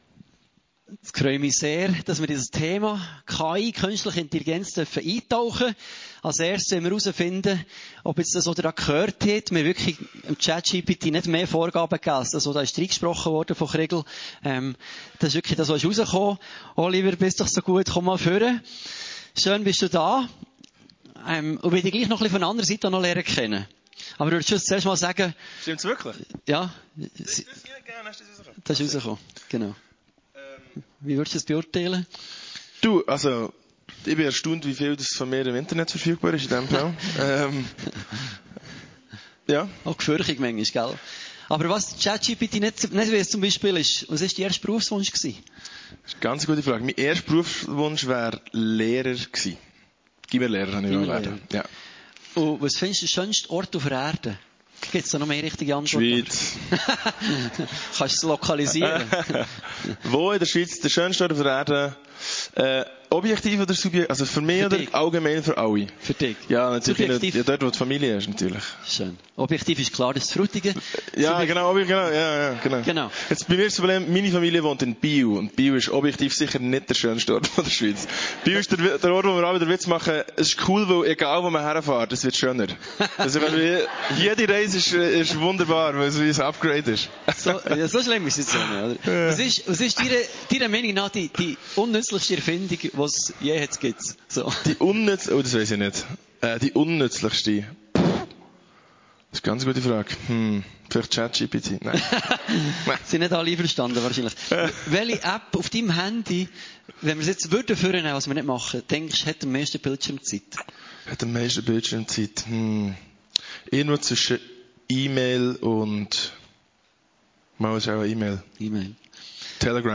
FMG Frutigen - Talk zu künstlicher Intelligenz